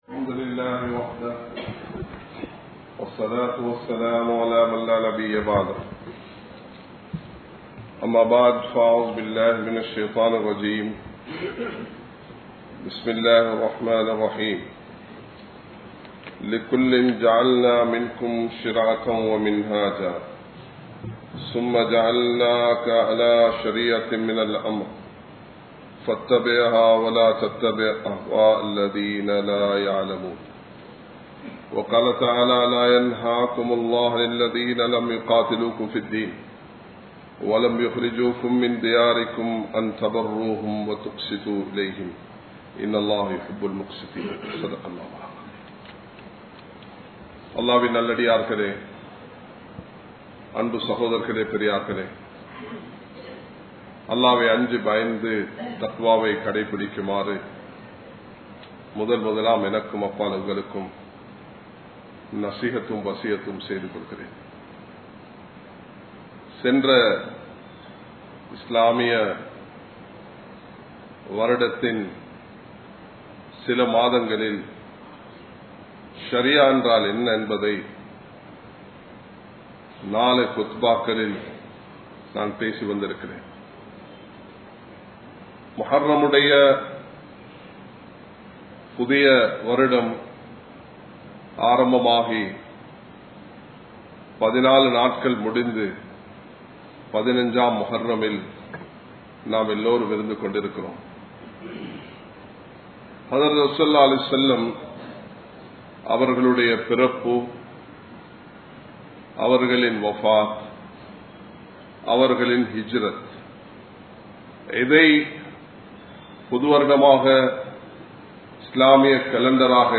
Shariya Entral Enna? (ஷரிஆ என்றால் என்ன?) | Audio Bayans | All Ceylon Muslim Youth Community | Addalaichenai
Samman Kottu Jumua Masjith (Red Masjith)